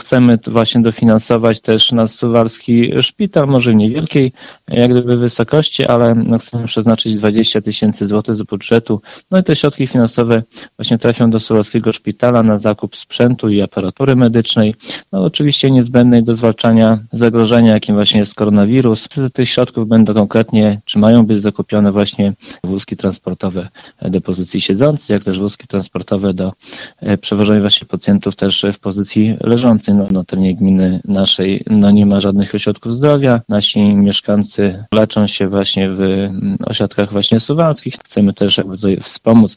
20 tysięcy złotych na zakup wózków transportowych przekaże suwalskiemu szpitalowi gmina Suwałki. W ten sposób samorząd chce wspomóc placówkę medyczną, w której leczą się mieszkańcy gminy. O szczegółach mówi wójt Zbigniew Mackiewicz: